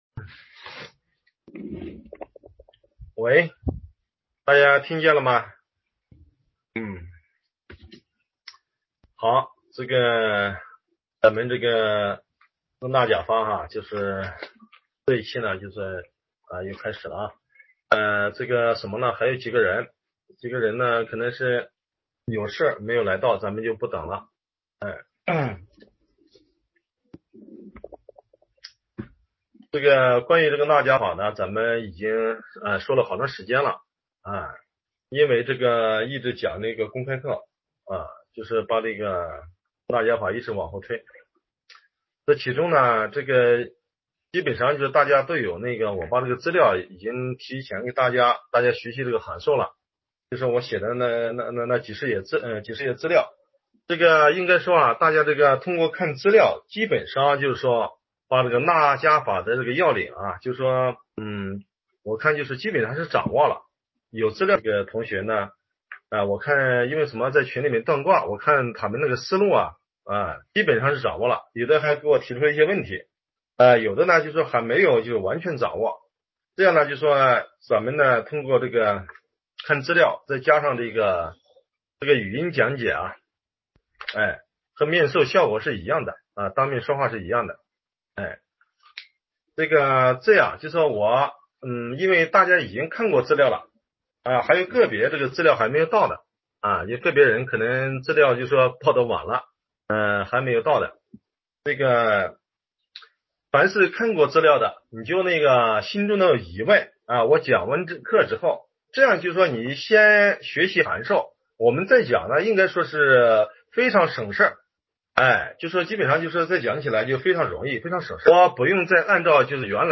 清晰度还不错，此六爻体系是道家秘密隐传的六爻体系，外面没有。